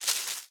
leaves4.ogg